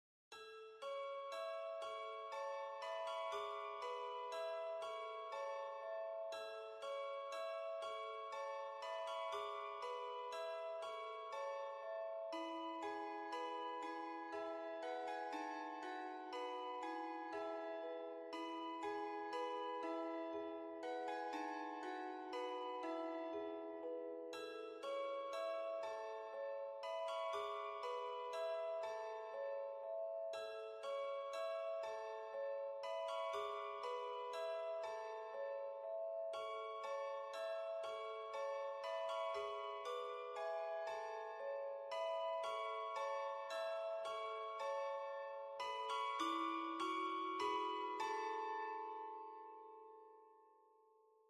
Musique : Nursery Rhyme : Oranges and lemons - chimes mix
comptine anglaise : Oranges et citrons - mélange de carillons